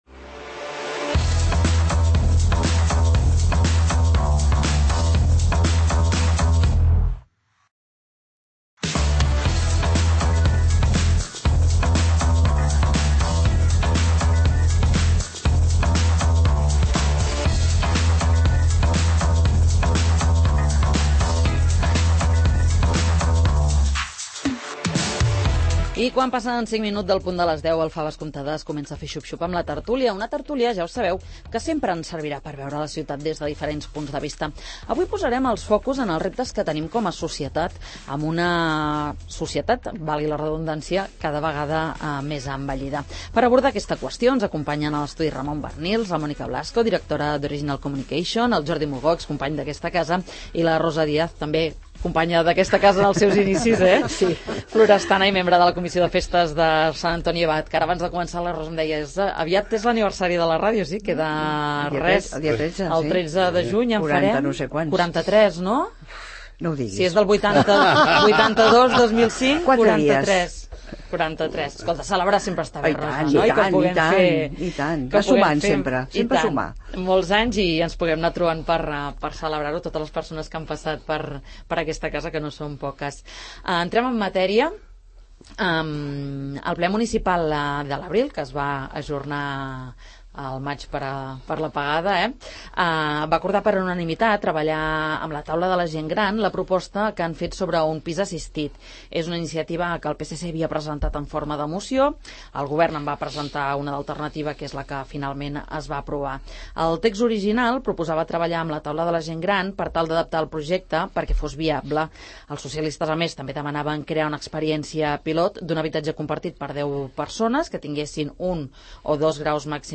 Com afronta l'administraci� l'envelliment de la poblaci�? En parlem a la tert�lia del 'Faves comptades'